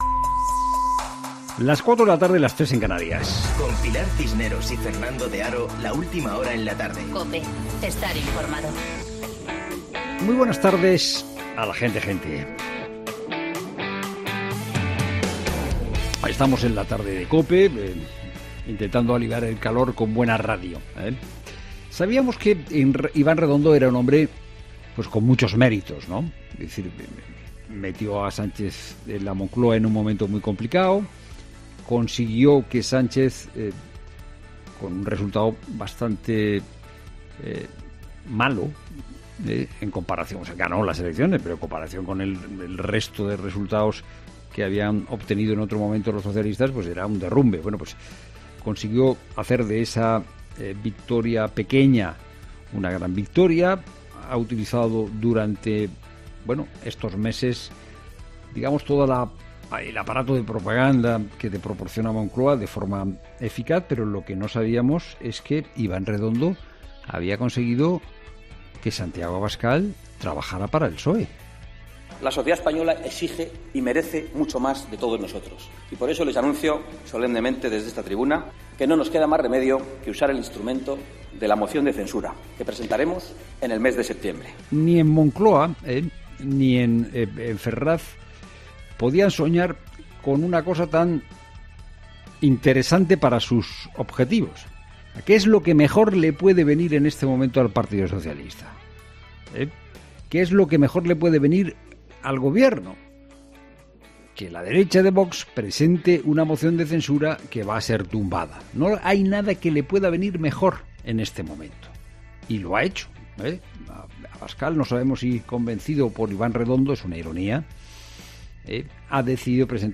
Monólogo de Fernando de Haro De Haro: ¿Amenaza Rufián diciendo que sin justicia no hay paz?